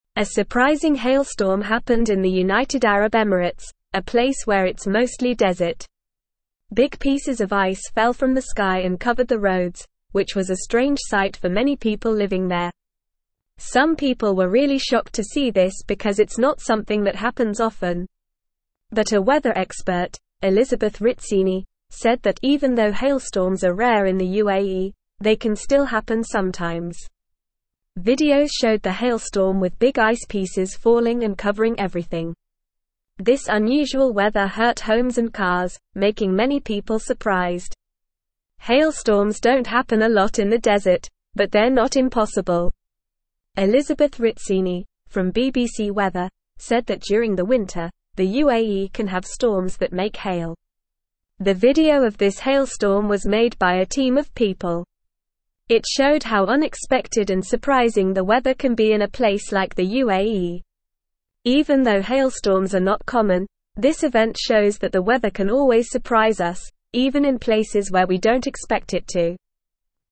Normal
English-Newsroom-Lower-Intermediate-NORMAL-Reading-Ice-Storm-in-United-Arab-Emirates-A-Surprising-Weather-Event.mp3